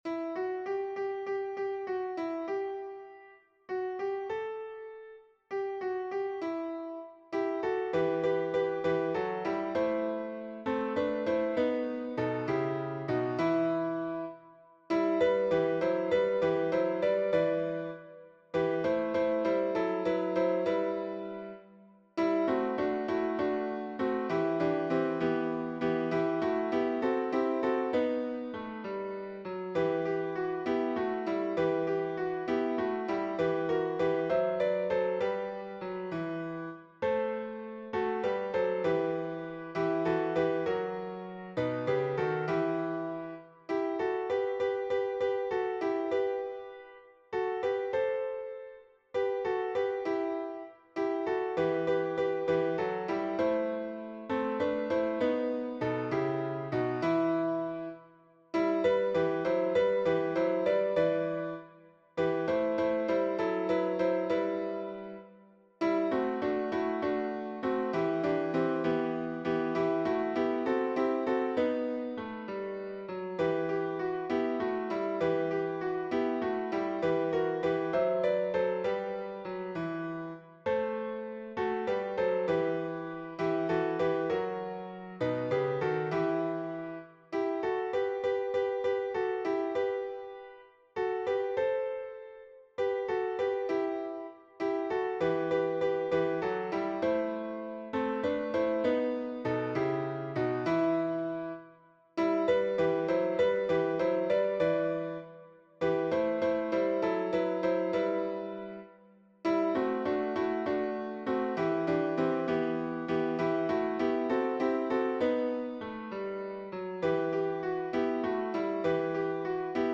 MP3 version piano
Toutes les voix